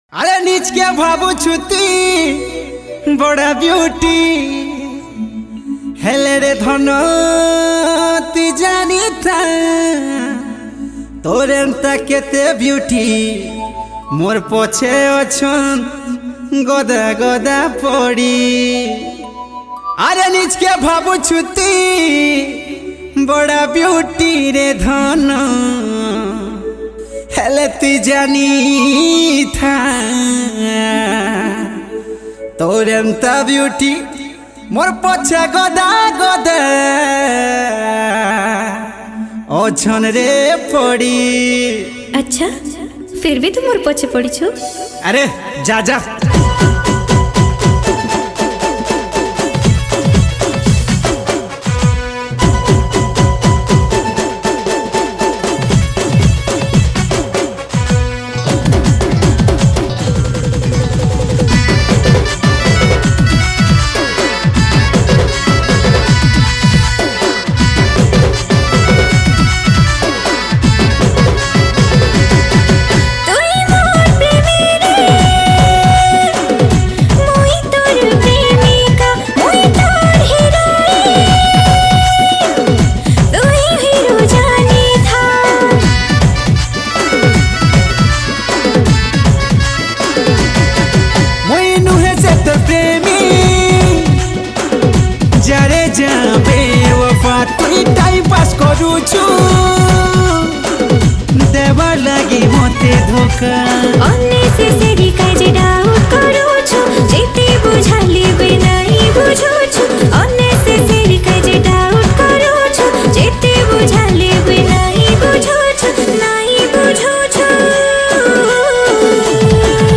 Sambapuri Single Song 2022 Songs Download